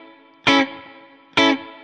DD_StratChop_130-Amaj.wav